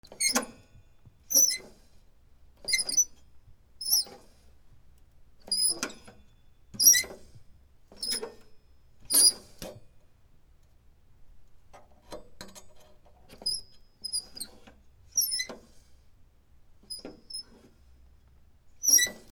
小さな鉄の扉